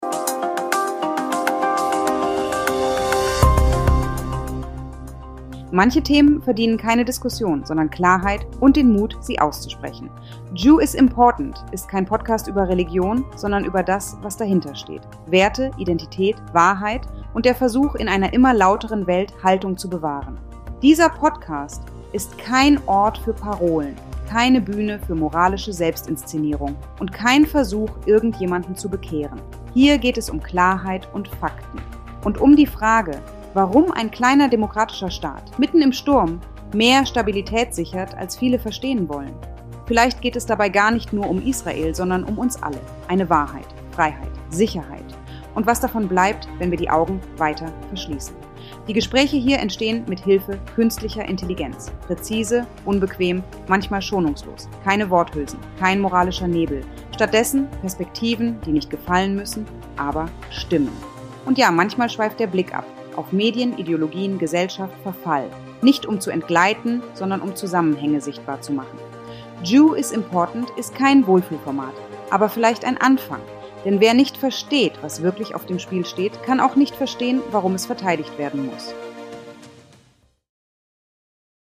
© 2025 AI-generated content. All rights reserved.